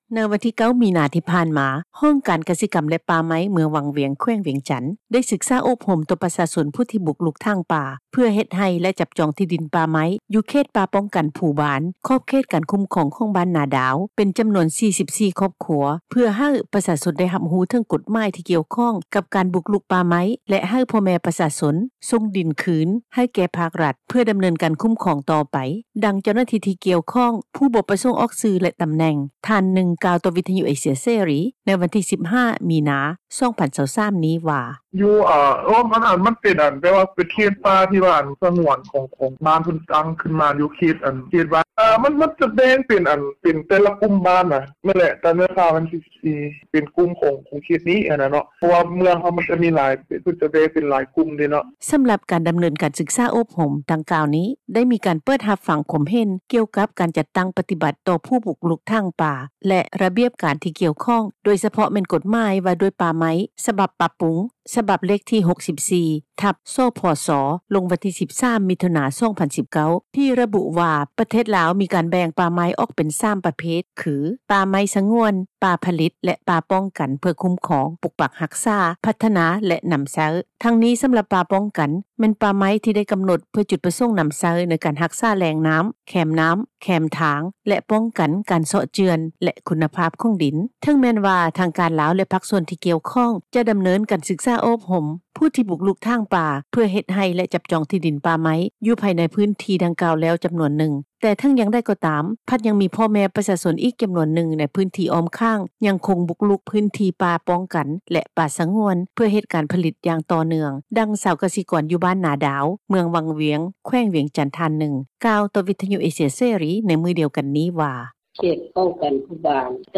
ດັ່ງ ເຈົ້າໜ້າທີ່ ທີ່ກ່ຽວຂ້ອງ ຜູ້ບໍ່ປະສົງອອກຊື່ ແລະ ຕໍາແໜ່ງ ທ່ານນຶ່ງ ກ່າວຕໍ່ວິທະຍຸ ເອເຊັຽ ເສຣີ ໃນມື້ວັນທີ 15 ມີນາ 2023 ນີ້ວ່າ:
ດັ່ງ ຊາວກສິກອນຢູ່ບ້ານນາດາວ ເມືອງວັງວຽງ ແຂວງວຽງຈັນ ທ່ານນຶ່ງ ກ່າວຕໍ່ວິທຍຸ ເອເຊັຽ ເສຣີ ໃນມື້ ດຽວກັນນີ້ວ່າ:
ດັ່ງ ເຈົ້າຂອງເຮືອນພັກ ຢູ່ເມືອງວັງວຽງ ແຂວງວຽງຈັນ ນາງນຶ່ງກ່າວວ່າ: